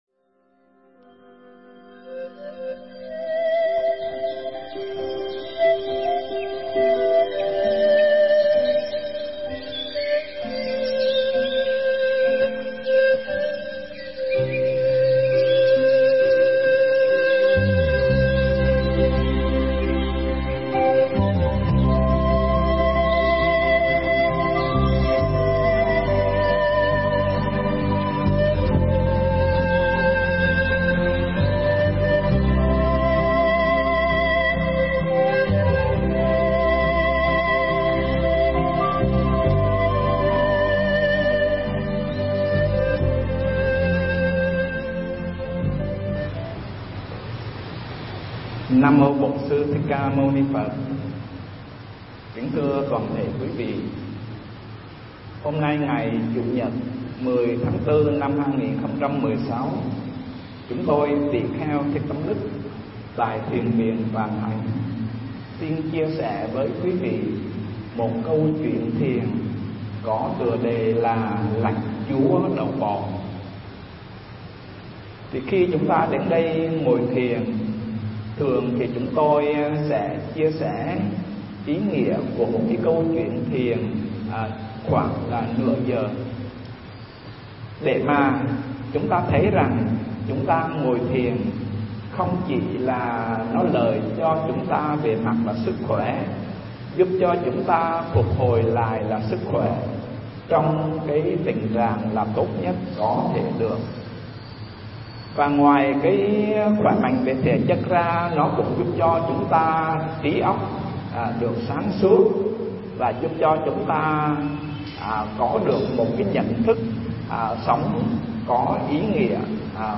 Mp3 Pháp Thoại Lãnh Chúa Đầu Bò